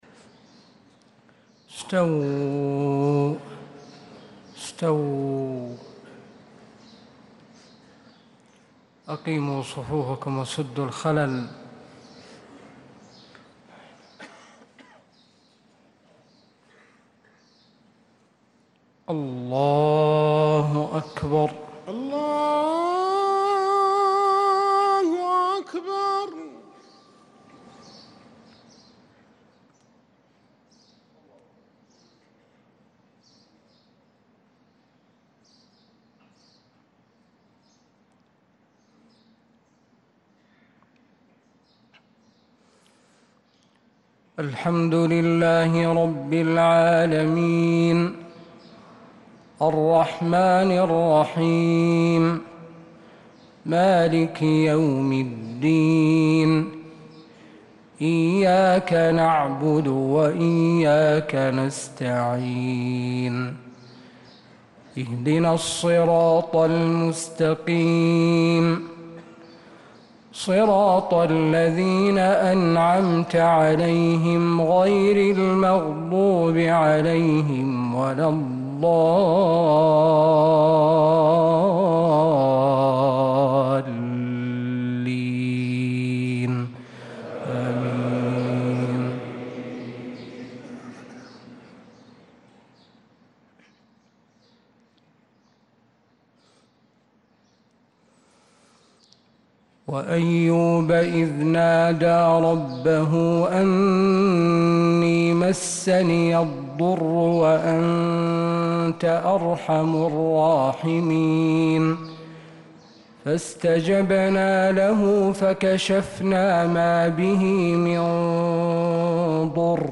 صلاة الفجر
تِلَاوَات الْحَرَمَيْن .